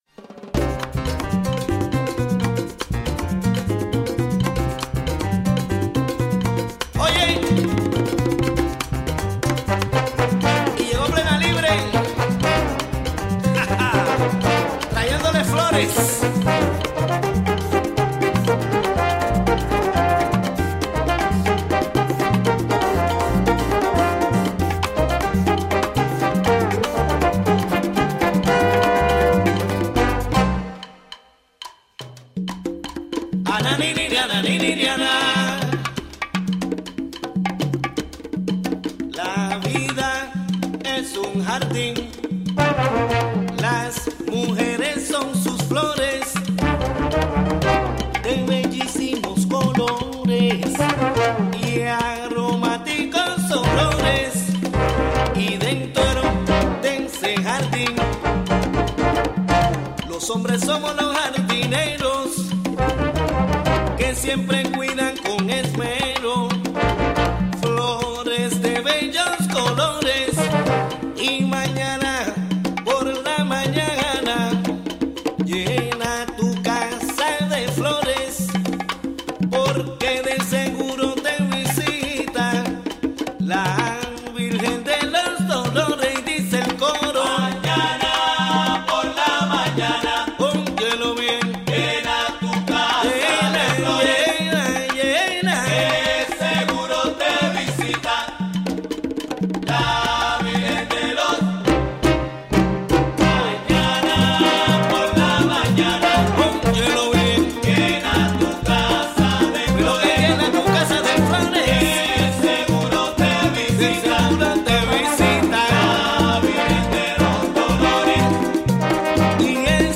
¿Qué cocinaré hoy? goes on the road to the GET, Gateways to Entrepreneurial Tomorrows, Career and Business Annual Expo and Conference.